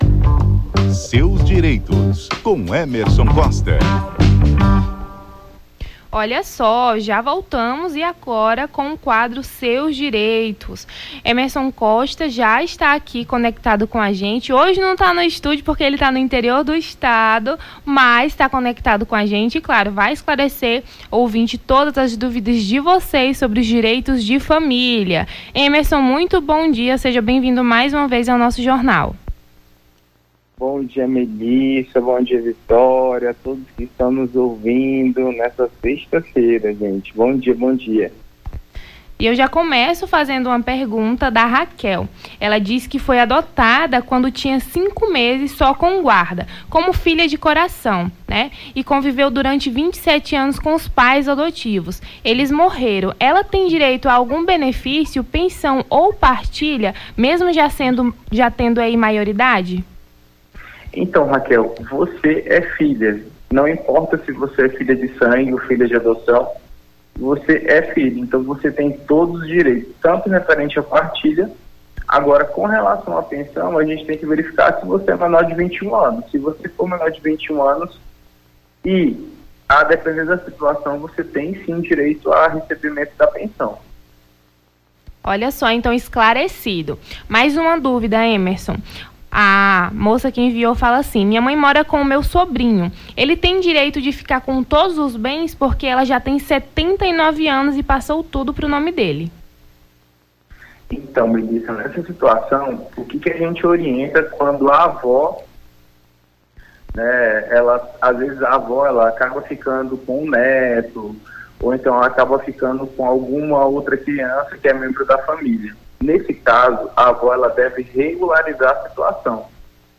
Seus Direitos: advogado esclarece dúvidas dos ouvintes sobre o direito da família